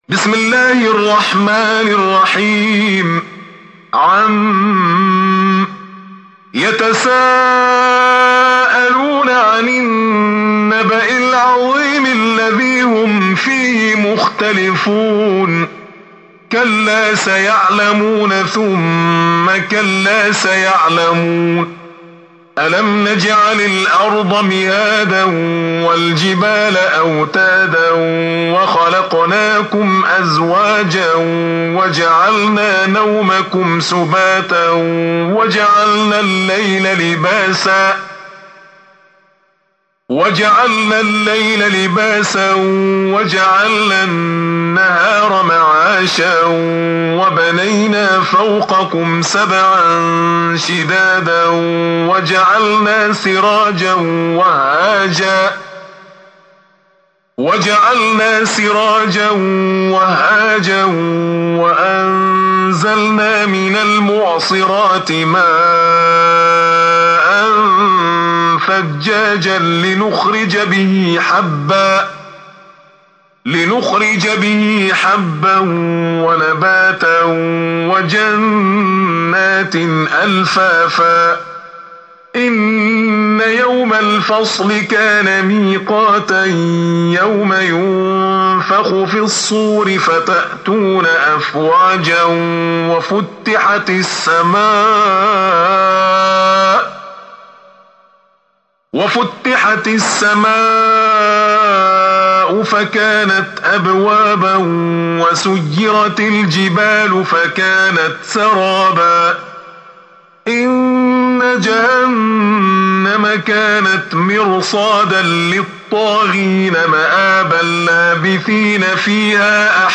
Surah Sequence تتابع السورة Download Surah حمّل السورة Reciting Murattalah Audio for 78. Surah An-Naba' سورة النبأ N.B *Surah Includes Al-Basmalah Reciters Sequents تتابع التلاوات Reciters Repeats تكرار التلاوات